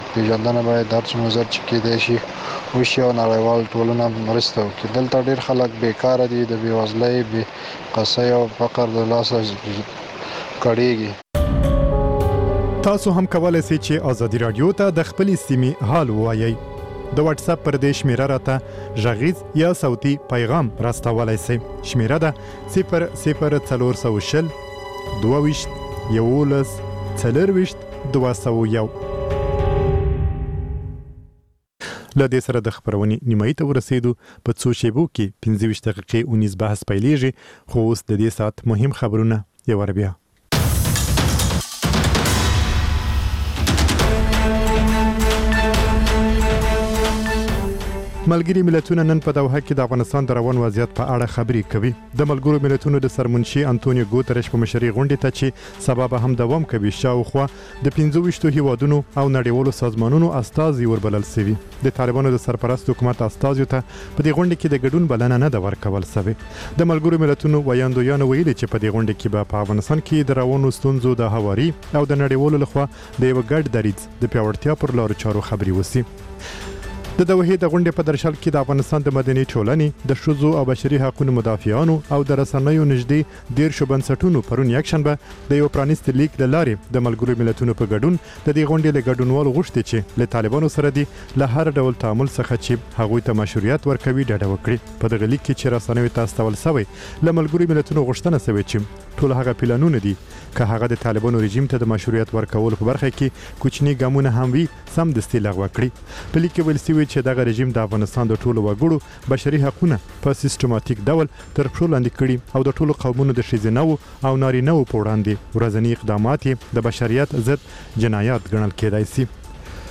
لنډ خبرونه - د لوبو له میدانه (تکرار)